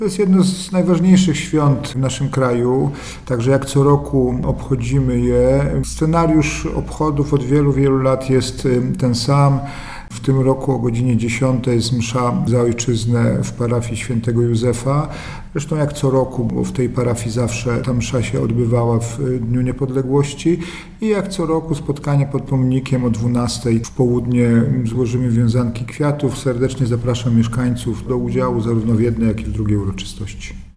Na uroczystości zaprasza mieszkańców Jacek Milewski, prezydent miasta.